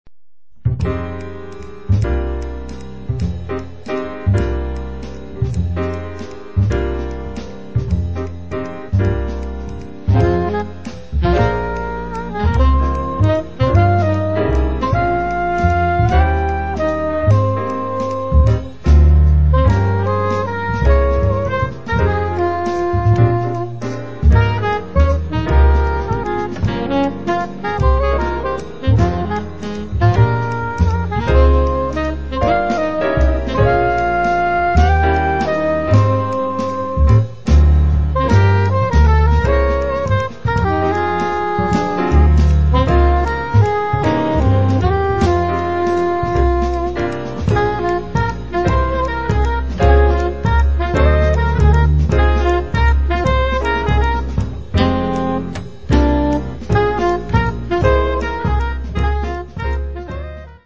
piano, electric piano
soprano saxophone, tenor saxophone
acoustic bass
drums